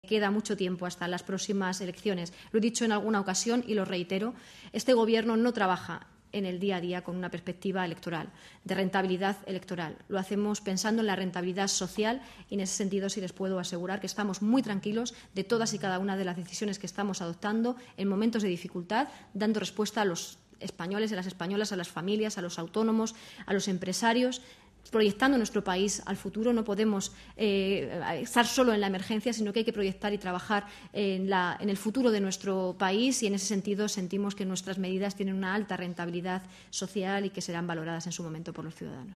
La portaveu del govern espanyol, Isabel Rodríguez, en roda de premsa al Palau de la Moncloa després del Consell de Ministres